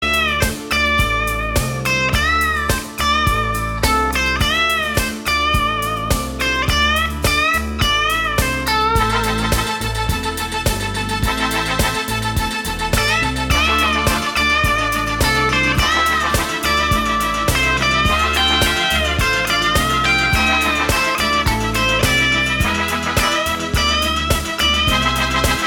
• Качество: 192, Stereo
без слов
инструментальные
электрогитара